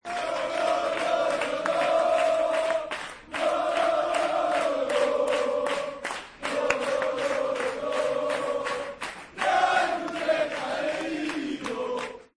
Así recibió la afición del Celta a su equipo a su llegada a Vigo
Redacción digital Madrid - Publicado el 28 ene 2016, 16:19 - Actualizado 16 mar 2023, 09:44 1 min lectura Descargar Facebook Twitter Whatsapp Telegram Enviar por email Copiar enlace La afición del Celta ha acudido en masa a recibir a su equipo al aeropuerto tras el pase a semifinales de la Copa del Rey al eliminar al Atlético de Madrid.